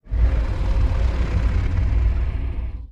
Sfx_creature_iceworm_idle_inhale_02.ogg